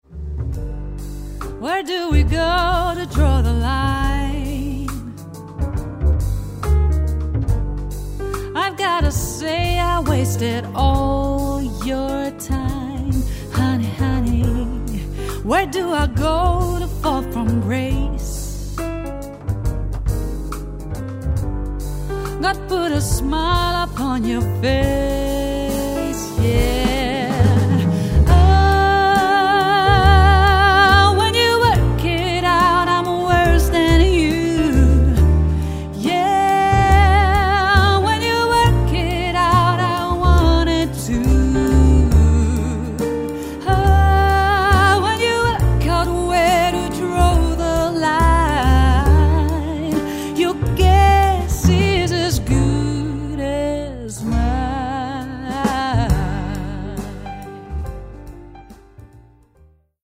JAZZ/POP